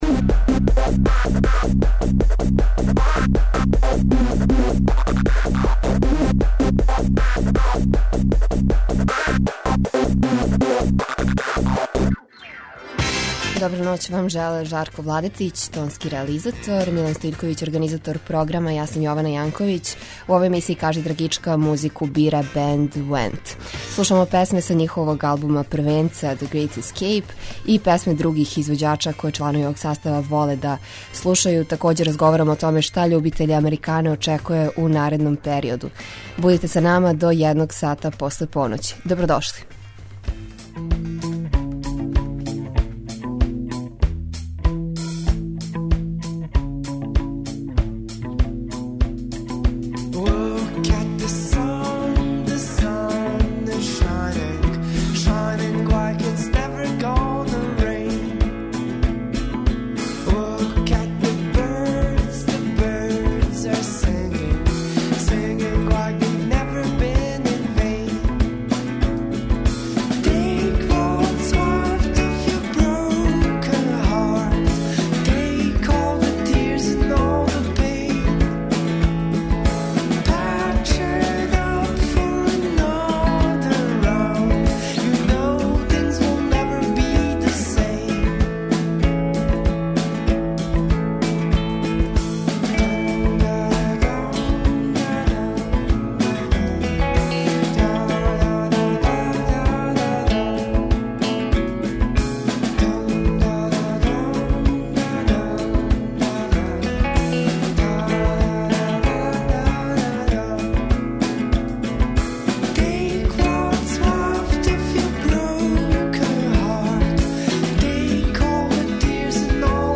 Овај састав полази од традиције америчког фолка, који допуњује елементима алтернативних жанрова и акустике.